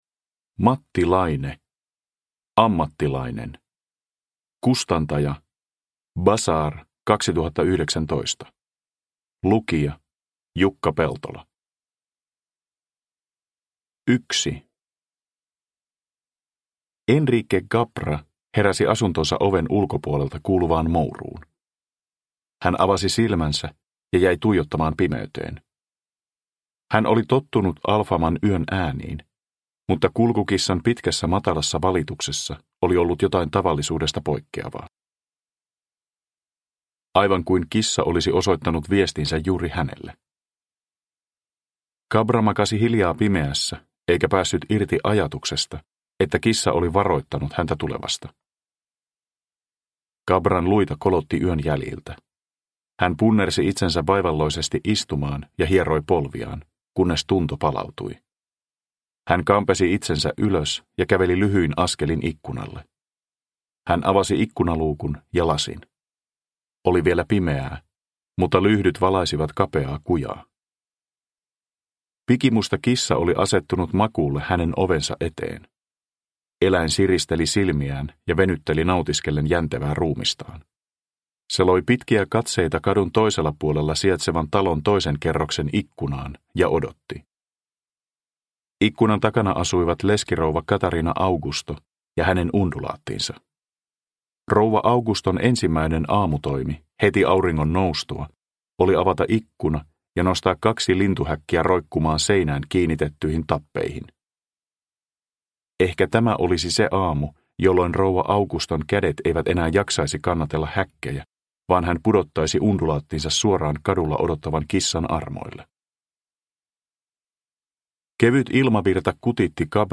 Ammattilainen – Ljudbok – Laddas ner
Uppläsare: Jukka Peltola